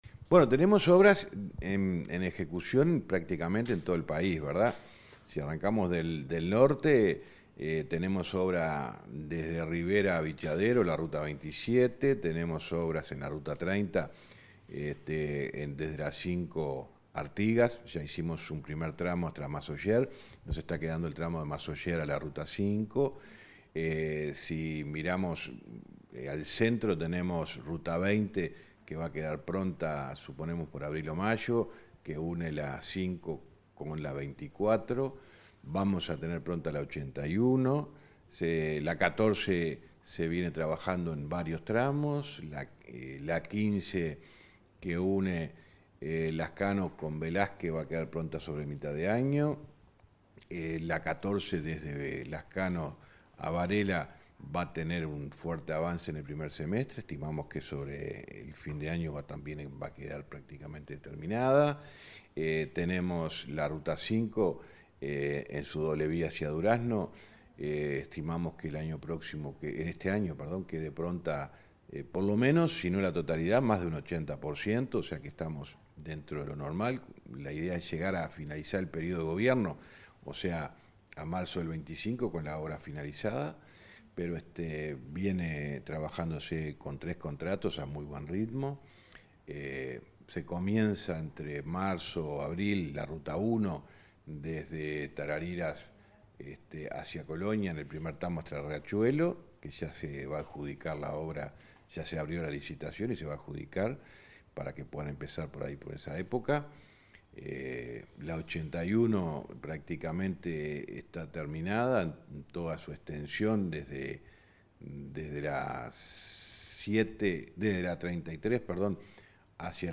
Entrevista al ministro de Transporte, José Luis Falero